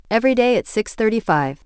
• 위에 음성이 Original, 아래 음성이 Reconstruction한 음성입니다.